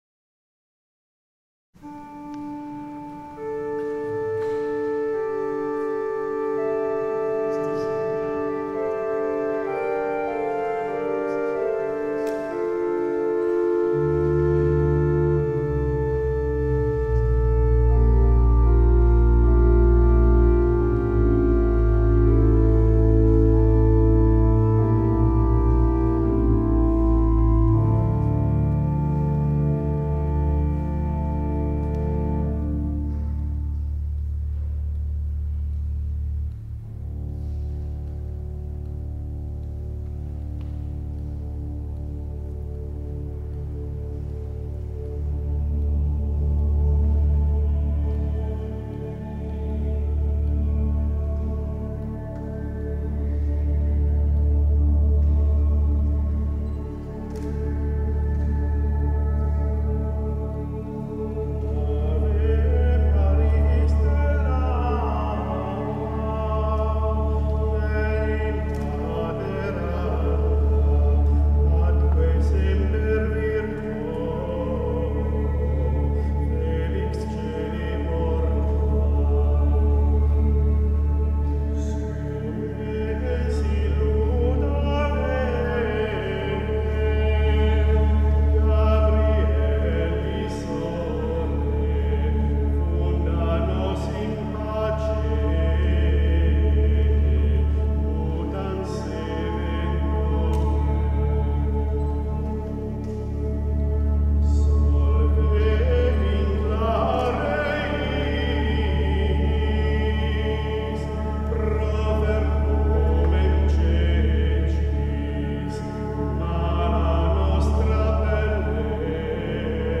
Coro voci miste e organo (Eseguita) (